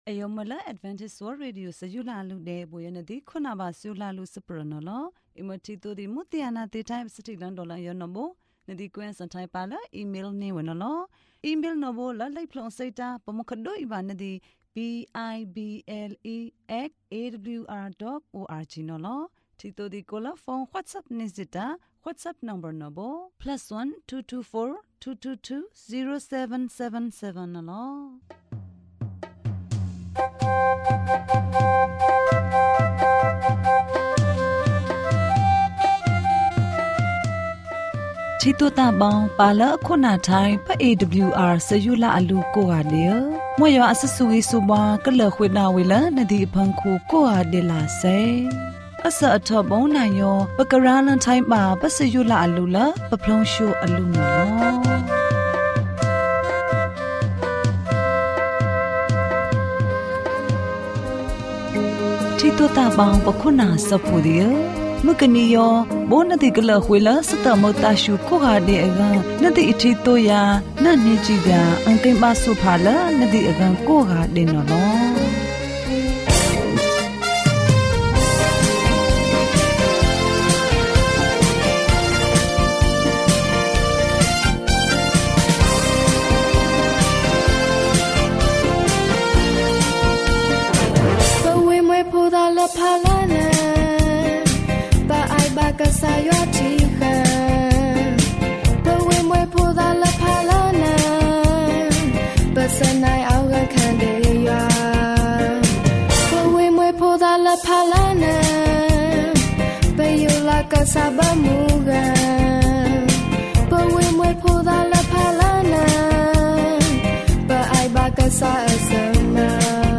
ယေရှုခရစ် ရှင်ပြန်ထမြောက်ခြင်းသည် ကျွန်ုပ်တို့အားလုံးအတွက် ကောင်းချီးမင်္ဂလာဖြစ်သည်။ ကျန်းမာခြင်းအကြောင်းအရာ။ ဓမ္မသီချင်း။ တရားဒေသနာ။